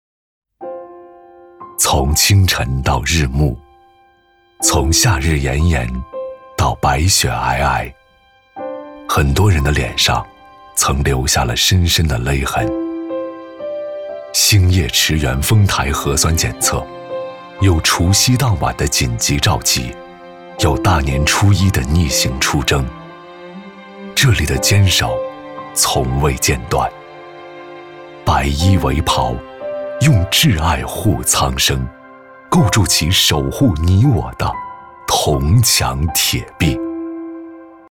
男49号-物理课件-探究平面镜成像时像距和物距的关系 男49年轻老师